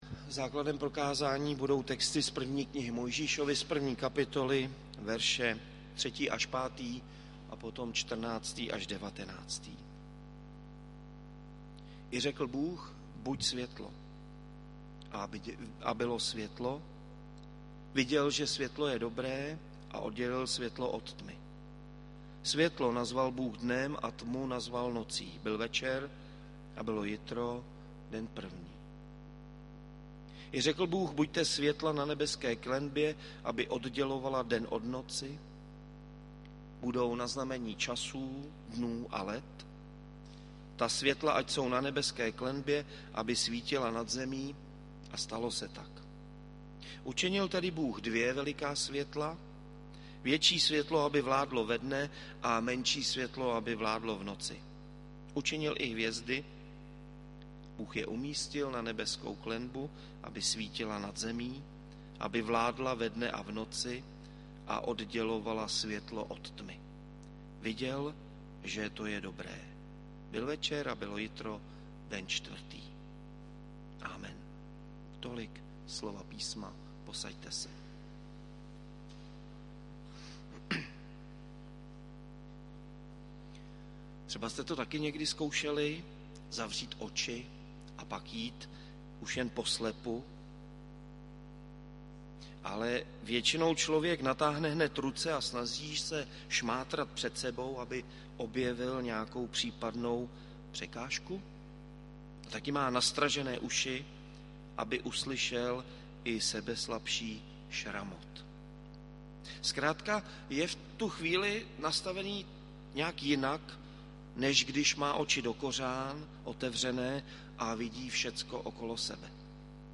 Novoroční bohoslužby 1. 1. 2023 • Farní sbor ČCE Plzeň - západní sbor
Bohoslužby s vysluhováním sv. Večeře Páně.